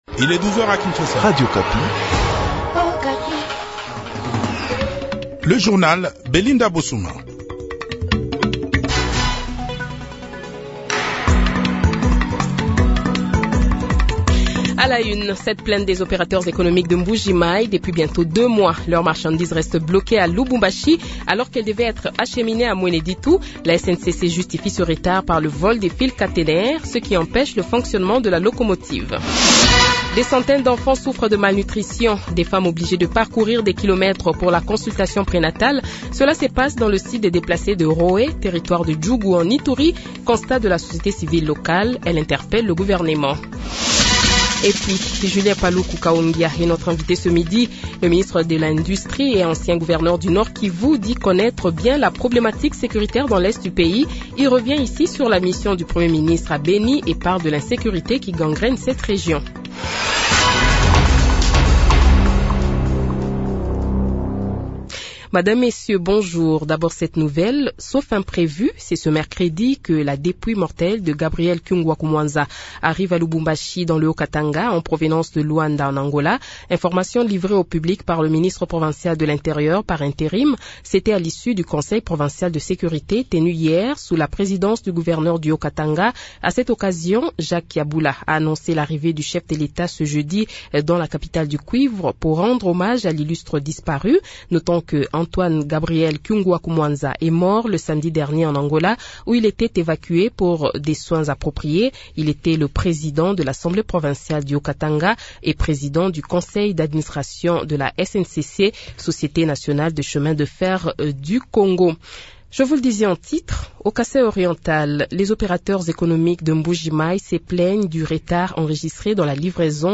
Invité : Julien PALUKU KAHONGYA, ministre de l’industrie et ancien gouverneur du nord kivu, sur la situation securitaire dans l'Est de la RDC